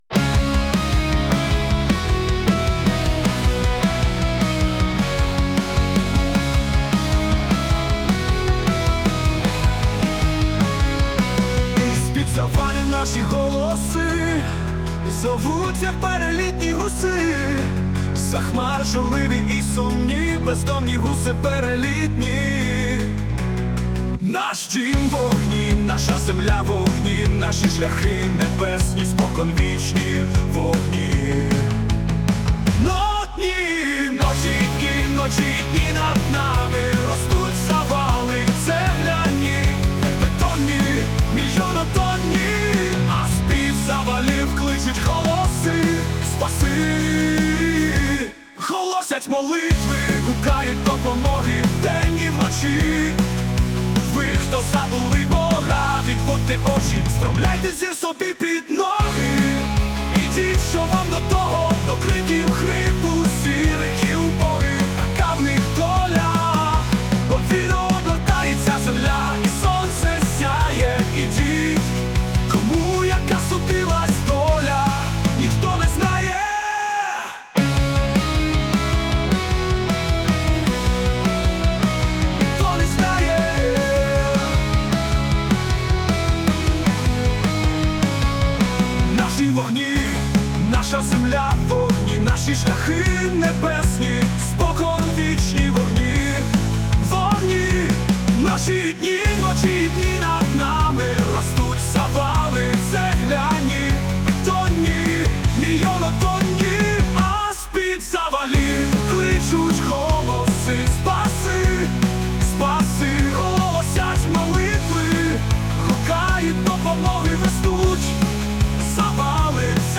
ДОЛЯ (музика ШІ в стилі хард-року)